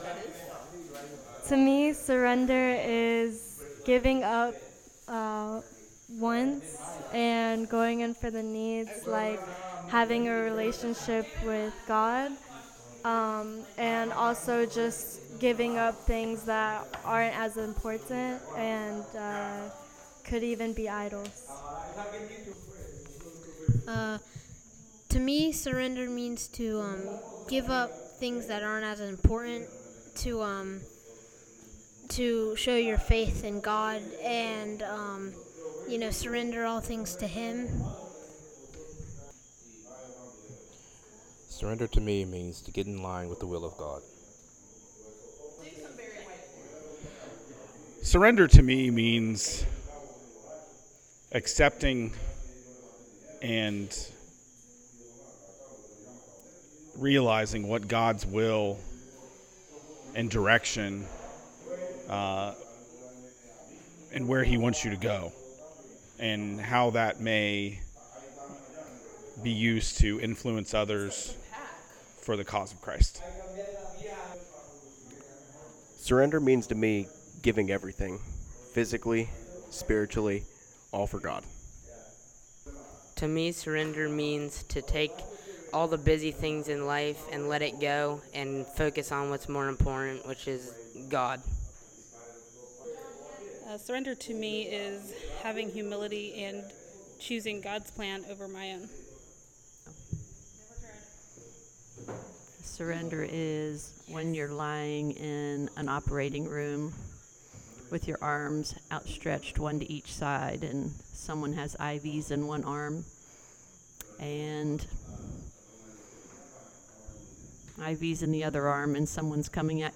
Sermons - ReFocus Church 2022